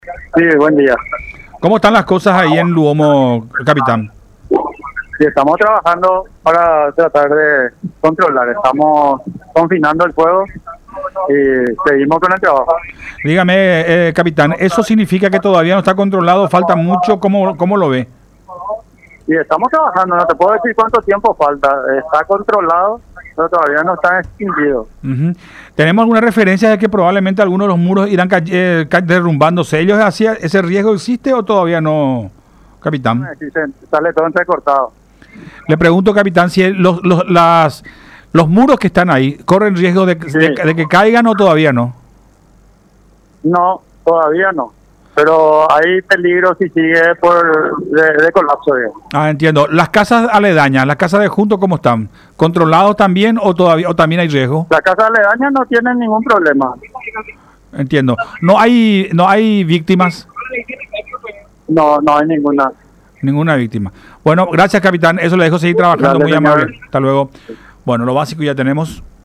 en conversación con Todas Las Voces por La Unión